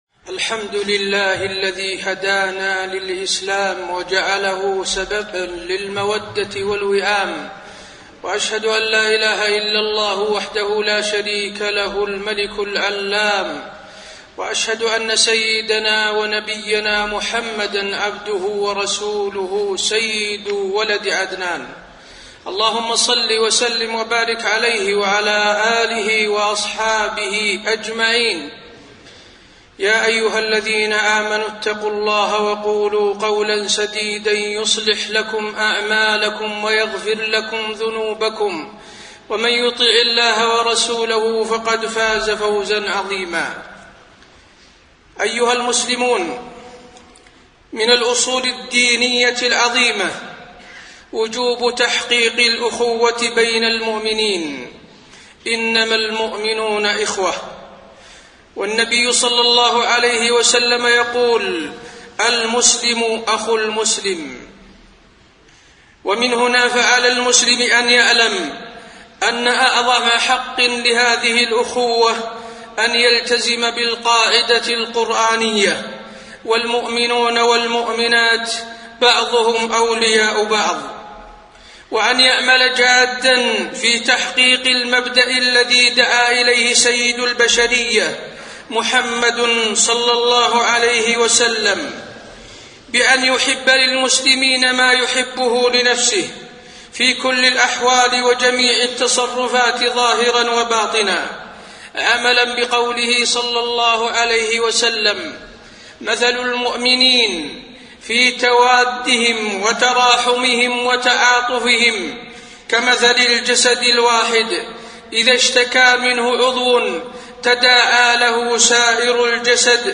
خطبة الجمعة 8-7-1432 هـ | موقع المسلم
خطب الحرم المكي